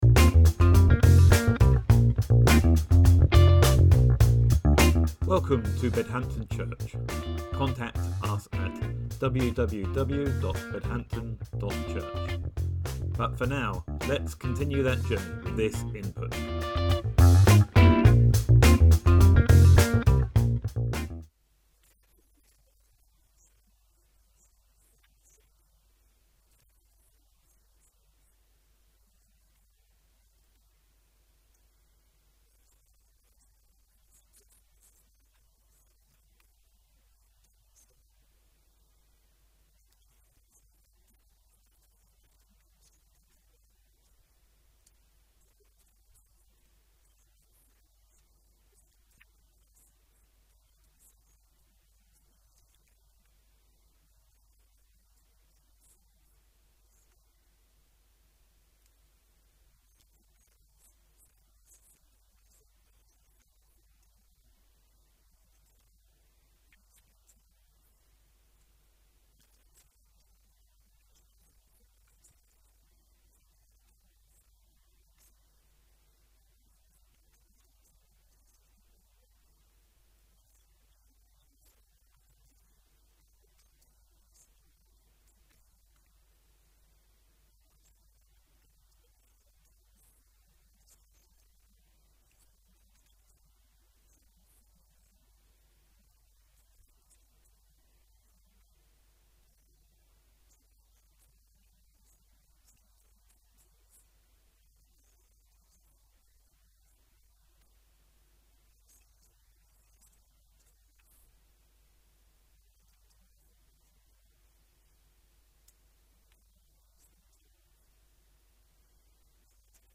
Sermon August 25th, 2024 – How Can I Resist Evil?